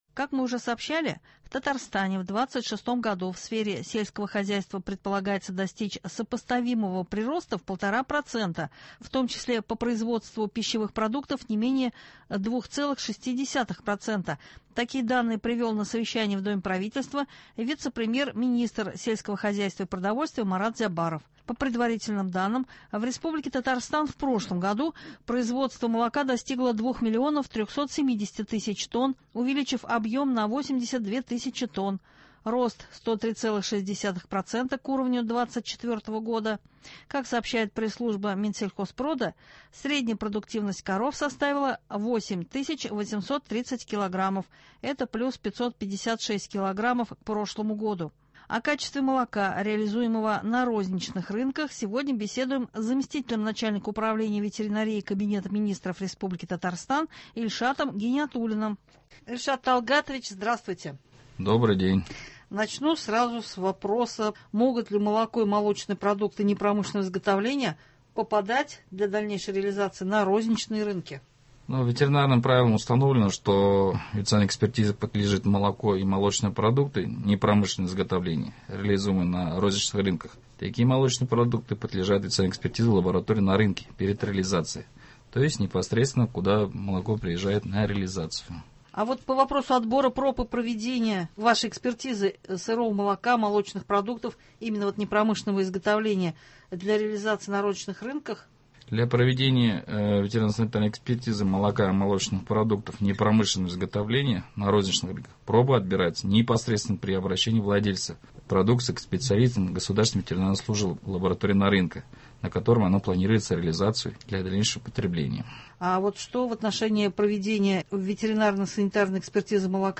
О качестве молока, реализуемого на розничных рынках , сегодня беседуем